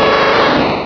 Cri de Tauros dans Pokémon Rubis et Saphir.